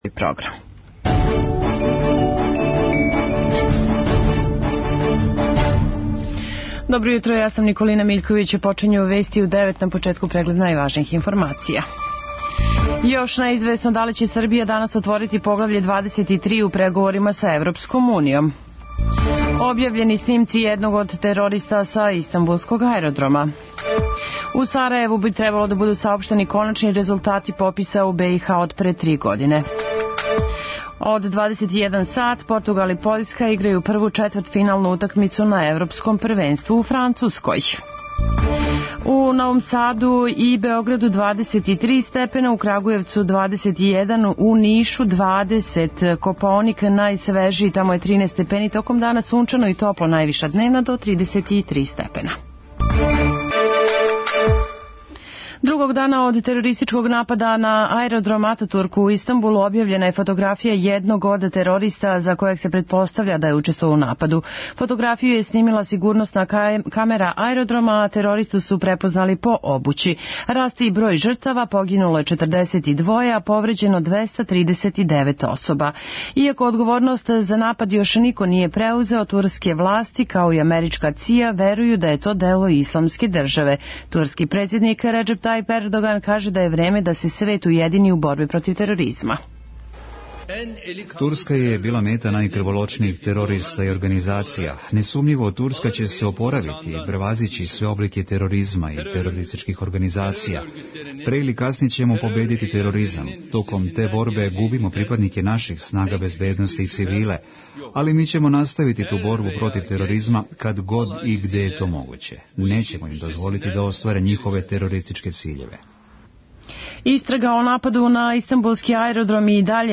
преузми : 3.98 MB Вести у 9 Autor: разни аутори Преглед најважнијиx информација из земље из света.